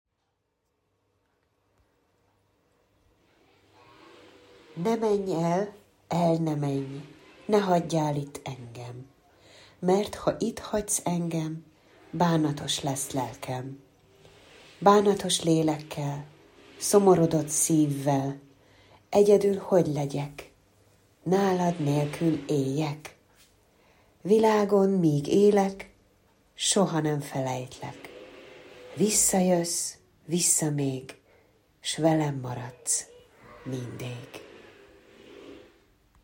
pronunciation Ne menj el by Bartók.mp3